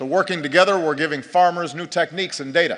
speech